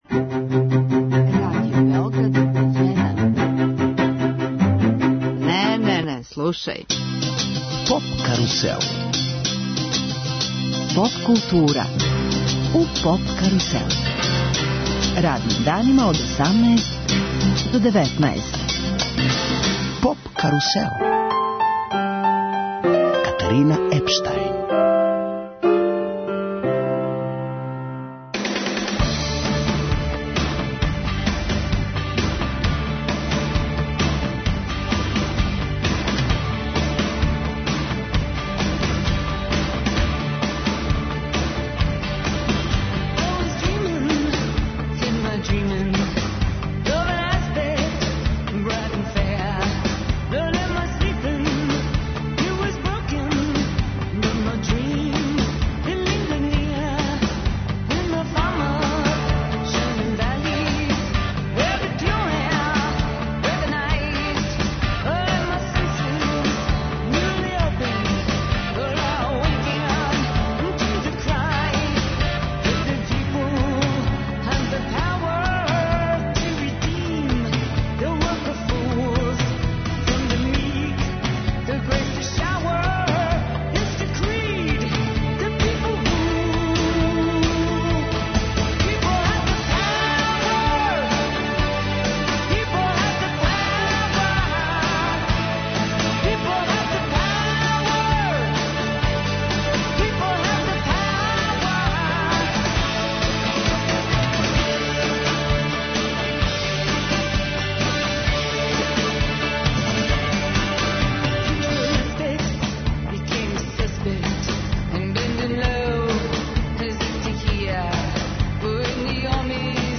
На Петроварадинској тврђави у Новом Саду данас почиње EXIT фестивал који је у знаку прославе 50 година од чувеног 'Лета љубави 1967'. Емисију реализујемо уживо из Новог Сада.